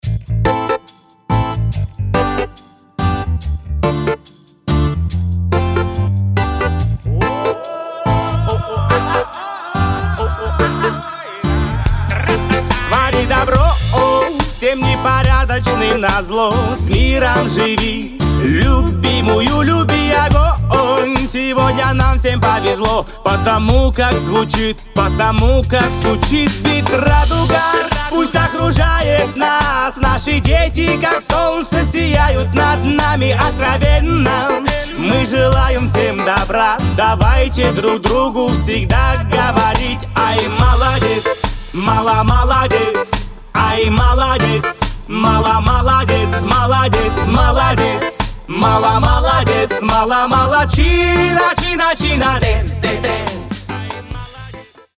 позитивные
мотивирующие
веселые
регги
добрые
на расслабоне